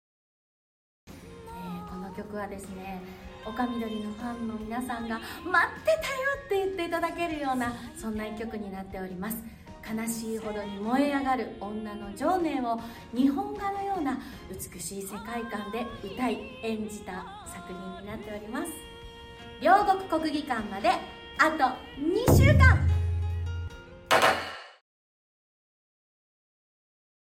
演歌
女性演歌歌手